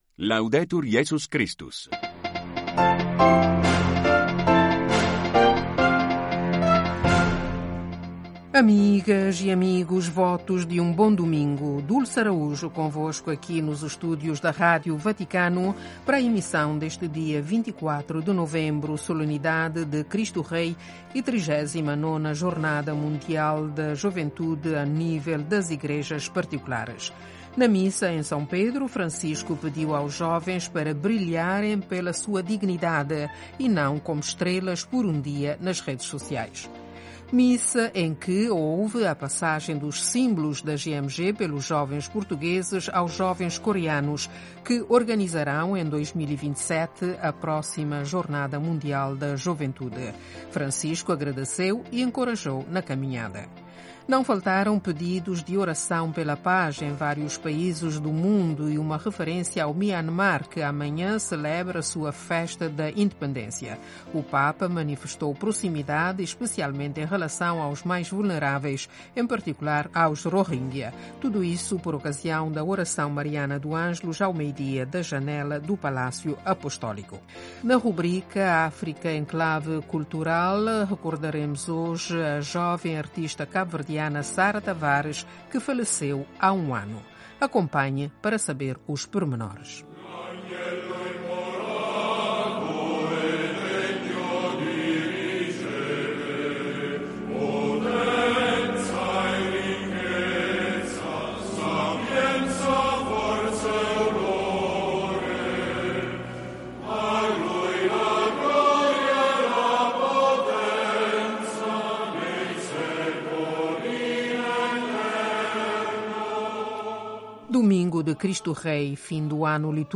1 Noticiário (17:00 CET) 27:00